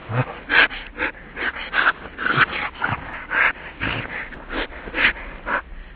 /hl2/sound/npc/antlion_guard/test/near/
alert_loop.ogg